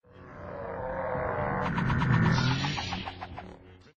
A lanvarok disc in flight